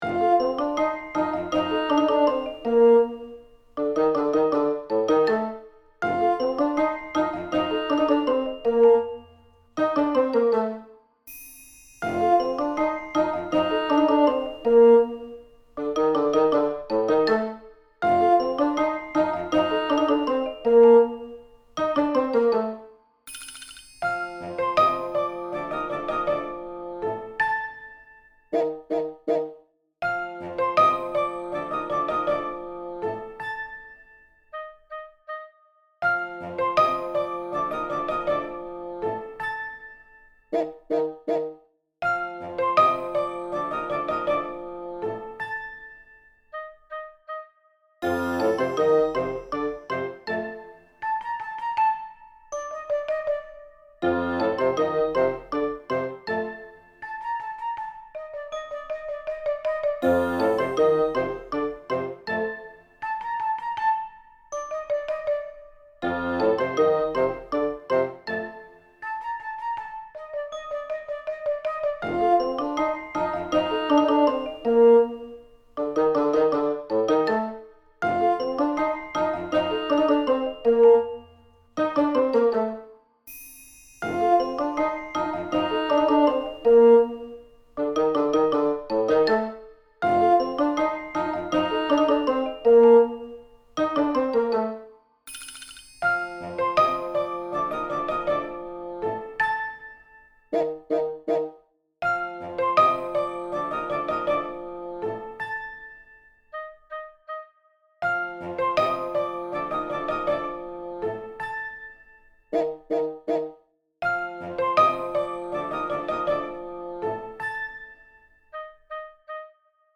気だるそうな日常系BGM
アコースティック 3:37